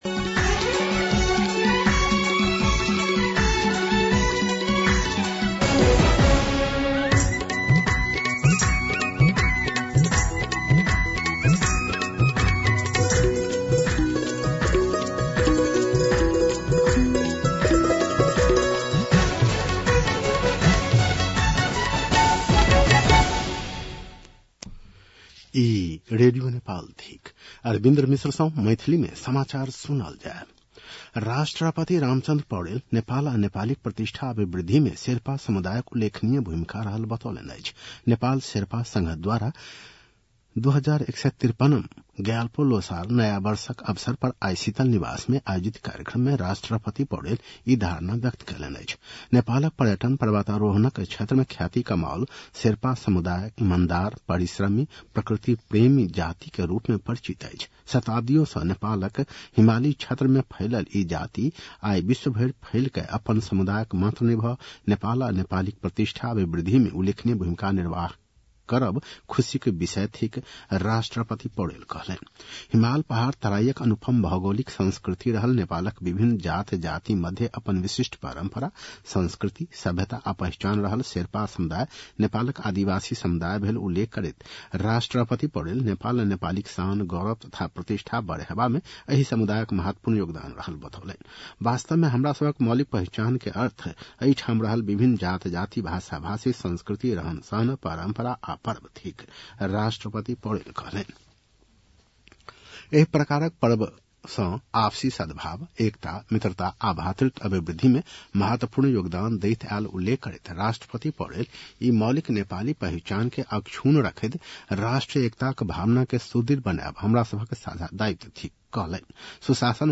मैथिली भाषामा समाचार : ५ फागुन , २०८२